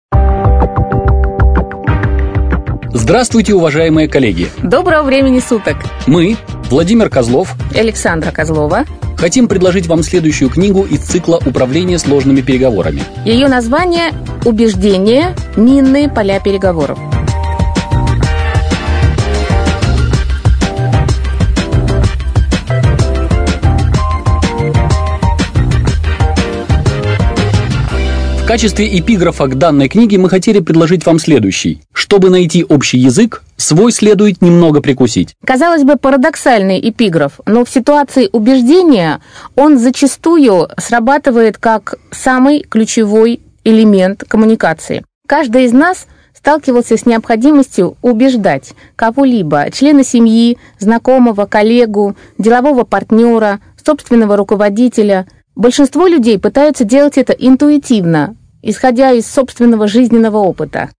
Аудиокнига Минные поля переговоров | Библиотека аудиокниг